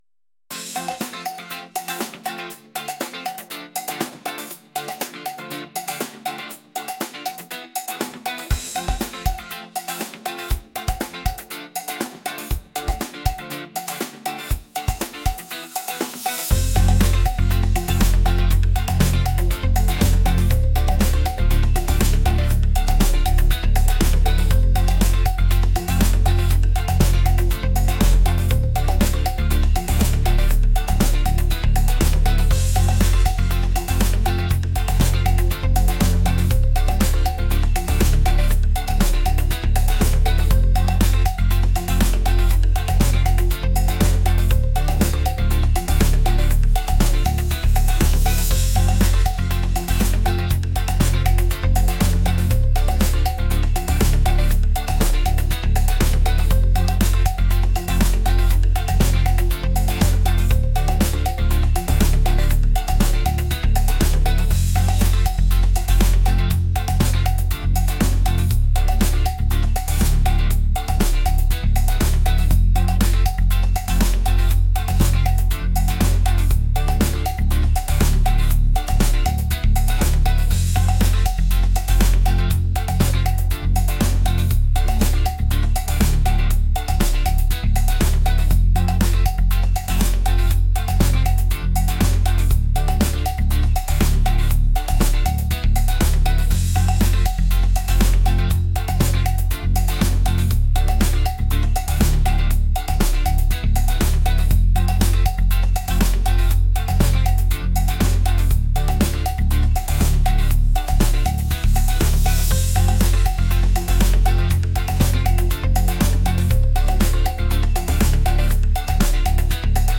pop | upbeat | energetic